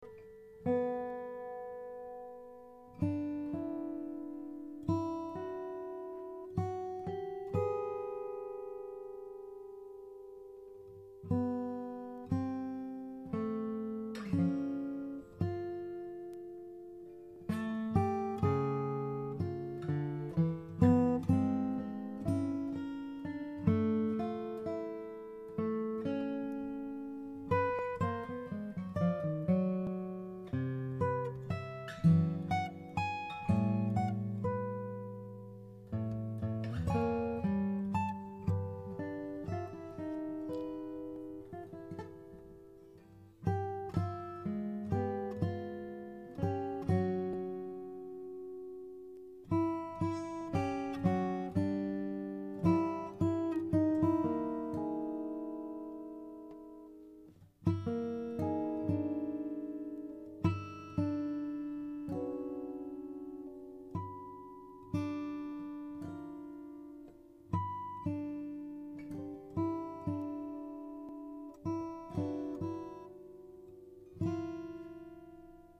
Duet for Two Guitars